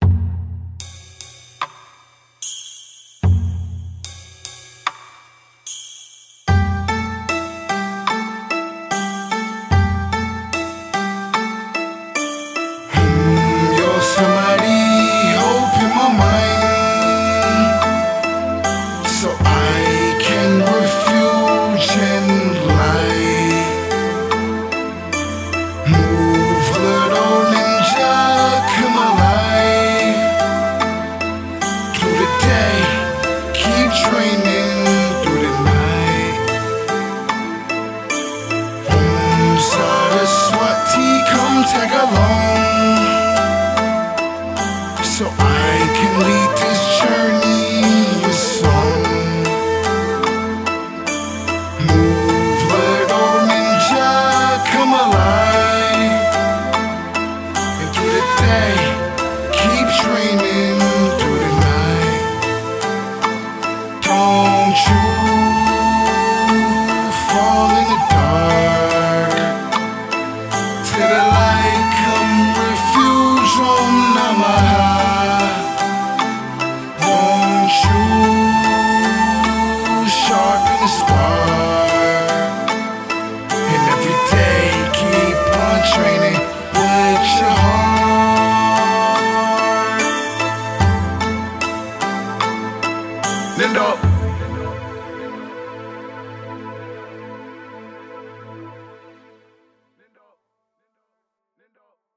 Recorded at AD1 Studios, Seattle, Washington, USA